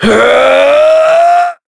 Shakmeh-Vox_Casting5.wav